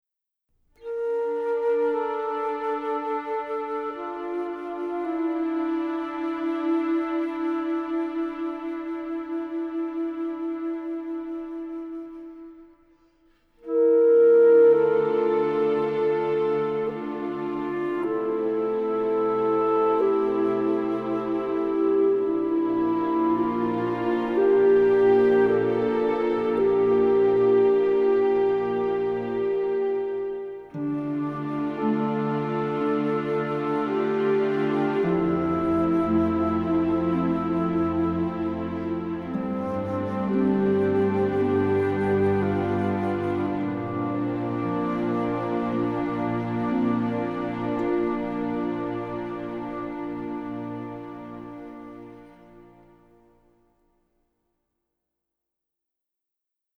Original Score